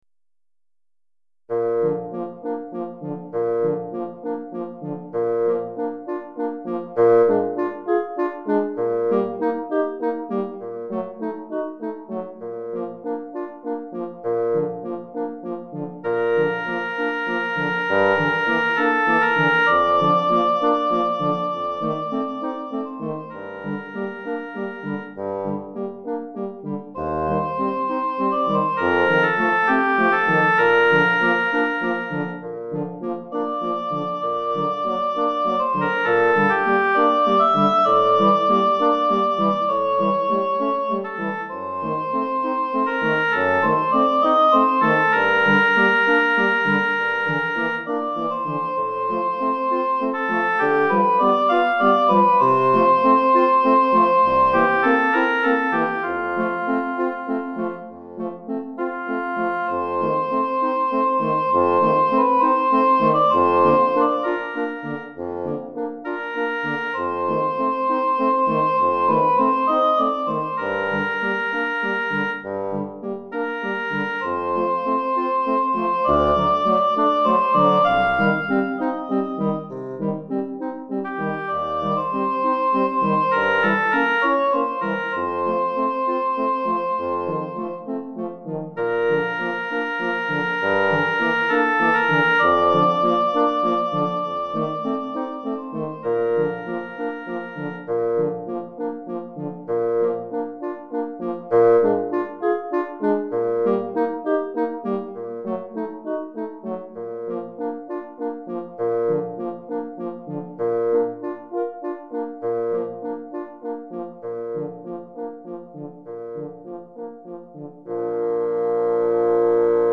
Hautbois 1 Clarinette en Sib 1 Cor en Fa 1 Basson